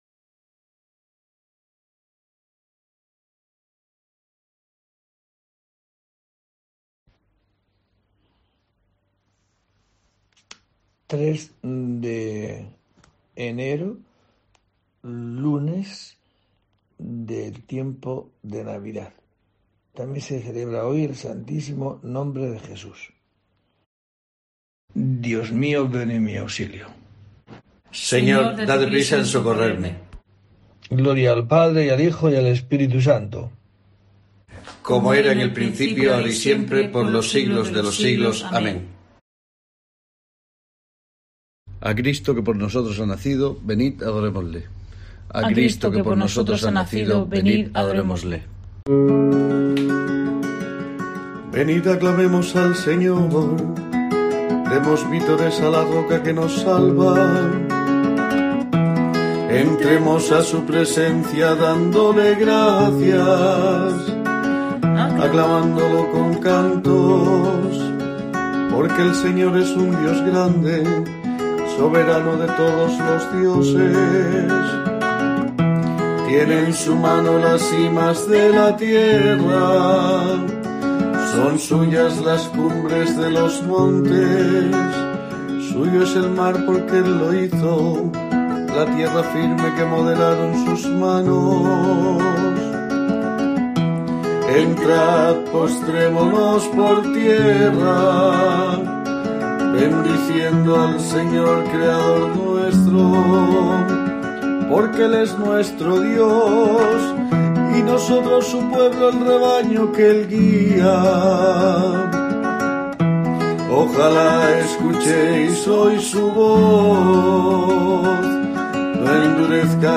03 de enero: COPE te trae el rezo diario de los Laudes para acompañarte